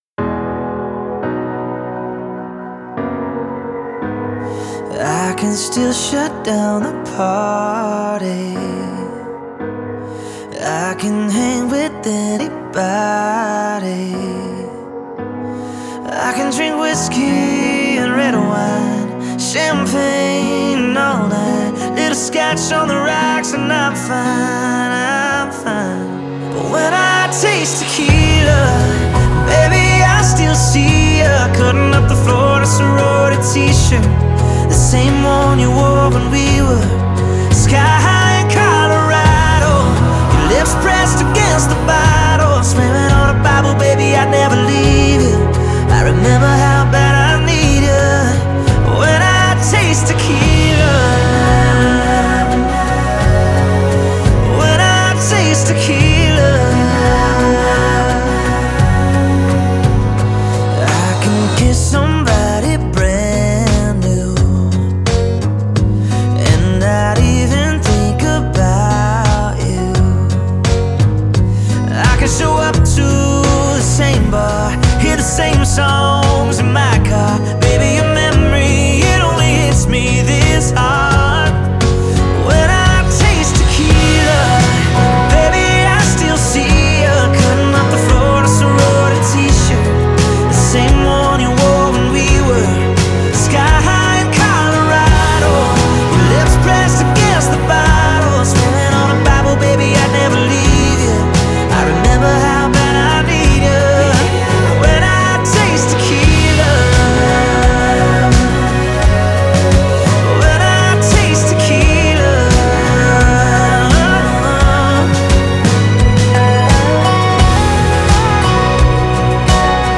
Country, Country Pop